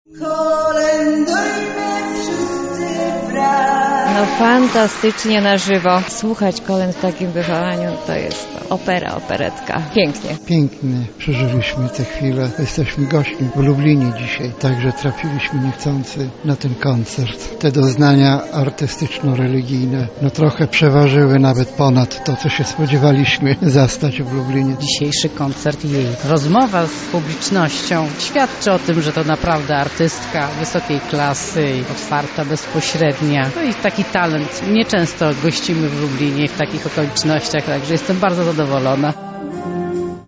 Najpiękniejsze polskie kolędy w jazzowej odsłonie – trwa Festiwal Bożego Narodzenia
Jazzowa wokalistka wypełniła śpiewem Bazylikę oo. Dominikanów. Nie zabrakło tych najbardziej znanych i tradycyjnych kolęd, jak i mniej popularnych pastorałek.
Ewa Bem wystąpiła w Lublinie w ramach Festiwalu Bożego Narodzenia, który potrwa do 10 stycznia.